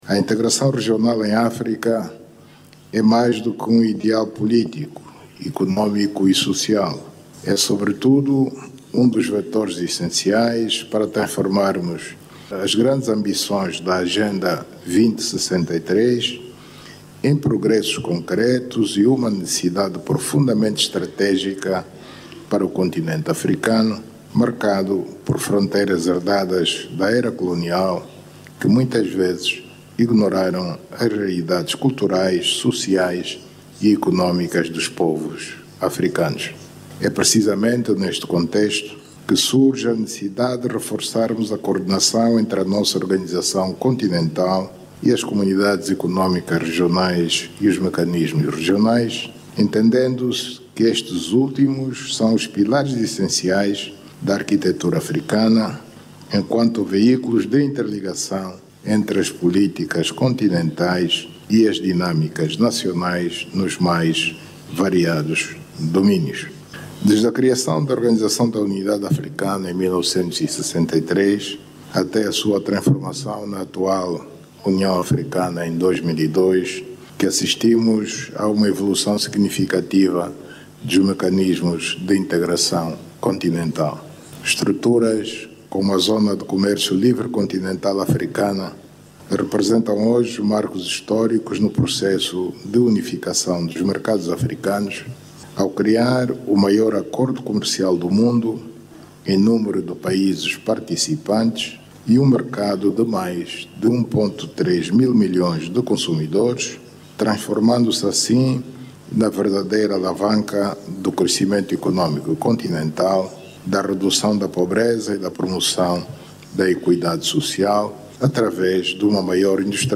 O estadista angolano discursava na abertura da VII Reunião de Coordenação Semestral da União Africana, ocasião em que sublinhou que a integração regional em África deve ser encarada não apenas como um ideal político, mas também como uma necessidade económica e social.